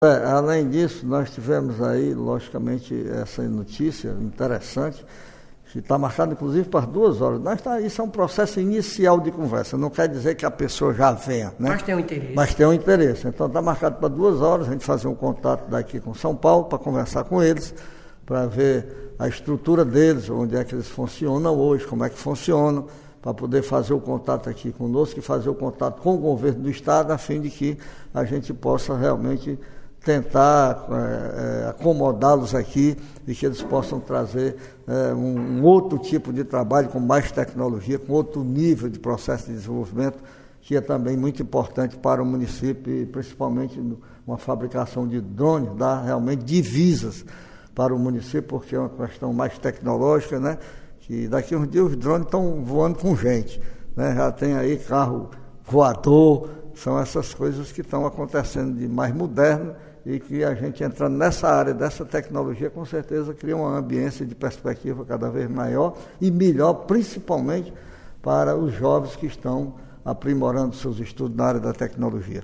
O município de Quixeramobim mantém contato com uma fábrica paulista de drones que demonstra interesse em instalar uma unidade na cidade. A informação foi revelada nesta quinta-feira, 3, pelo secretário de Agricultura do município, José Maria Pimenta, e posteriormente confirmada pelo prefeito Cirilo Pimenta, em entrevista à Rádio Campo Maior AM 840, emissora integrante do Sistema Maior de Comunicação.